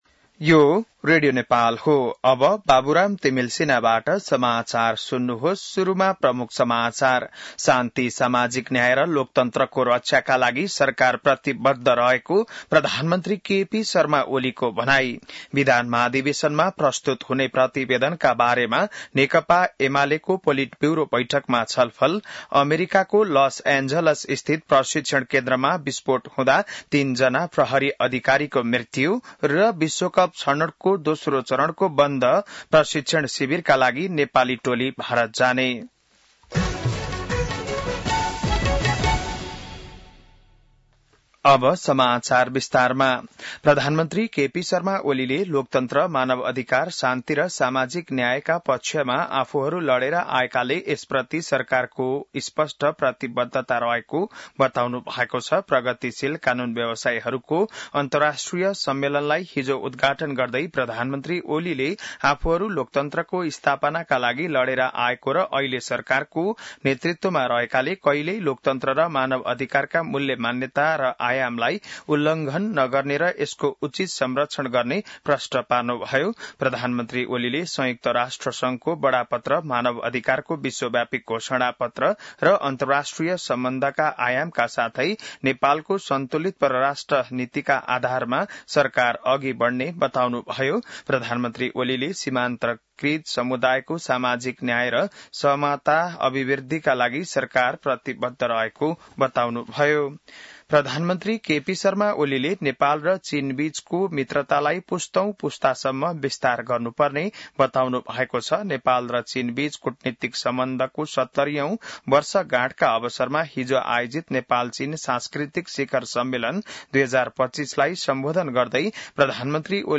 बिहान ९ बजेको नेपाली समाचार : ३ साउन , २०८२